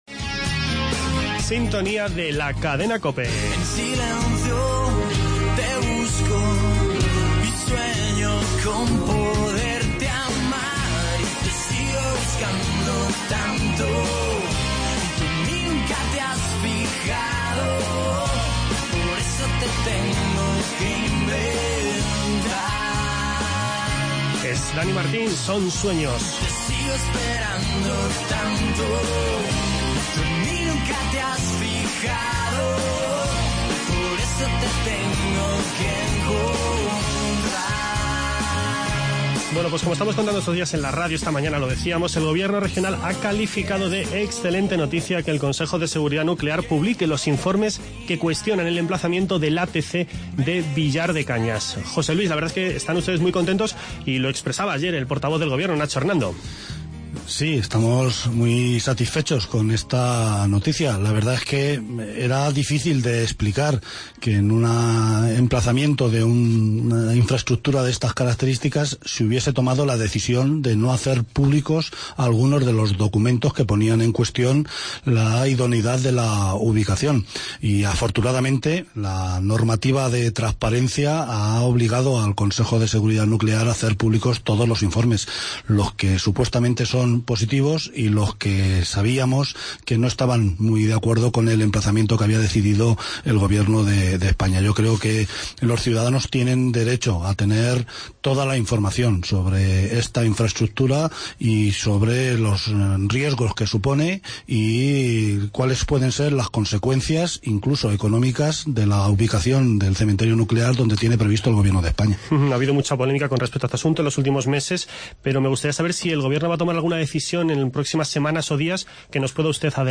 Escuche la entrevista con el vicepresidente del Gobierno, José Luis Martínez Guijarro, en la que hemos abordado la situación del ATC de Villar de Cañas, el futuro del Hospital del Tajo en Aranjuez y el Trasvase Tajo-Segura. A continuación, Miguel Ángel Valverde, alcalde de Bolaños de Calatrava, senador del PP por la provincia de Ciudad Real, y presidente de la Asociación para el Desarrollo del Campo de Calatrava, ha explicado en los micrófonos de COPE Castilla-La Mancha todo lo concerniente a la Ruta de la Pasión Calatrava.